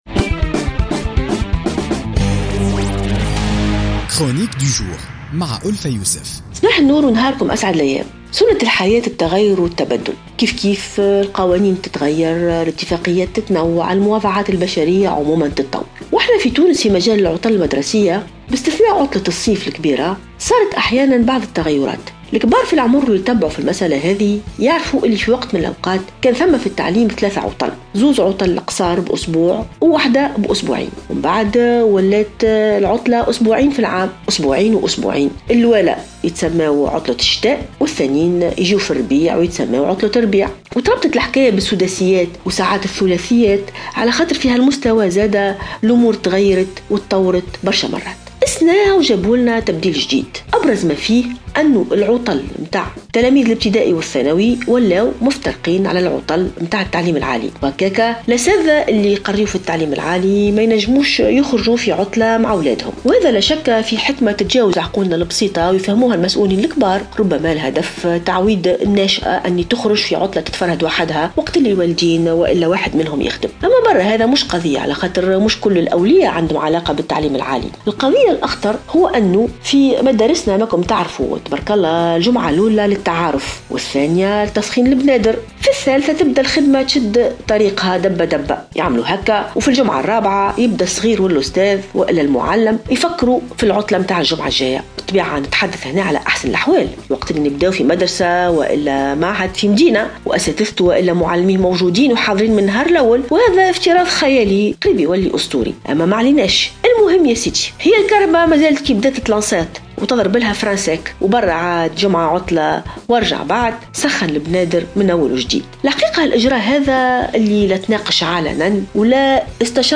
انتقدت الجامعية ألفة يوسف في افتتاحية اليوم الخميس 20 أكتوبر 2016 تغيير رزنامة العطل للسنة الدراسية الحالية.